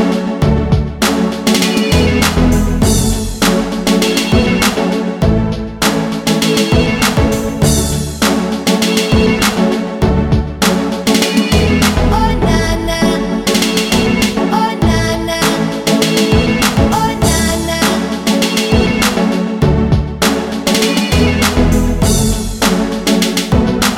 No Backing Vocals Or Rap R'n'B / Hip Hop 4:28 Buy £1.50